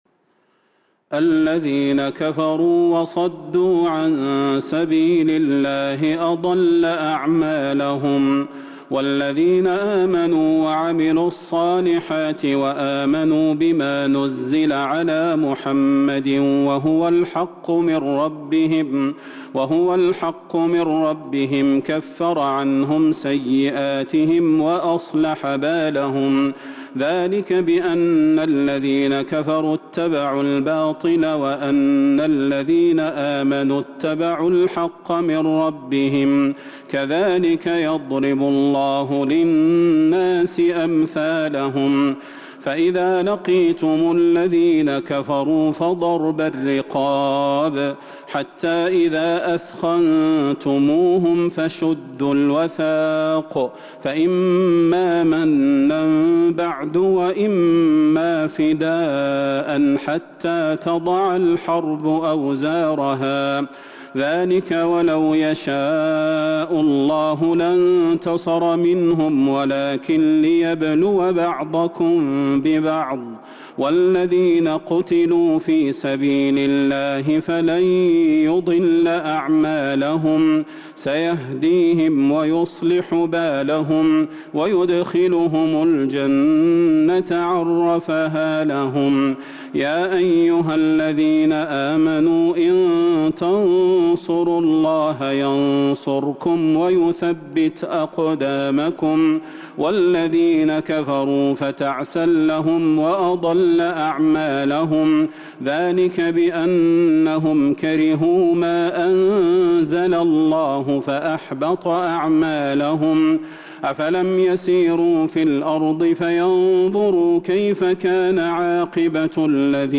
سورة محمد Surat Muhammad من تراويح المسجد النبوي 1442هـ > مصحف تراويح الحرم النبوي عام ١٤٤٢ > المصحف - تلاوات الحرمين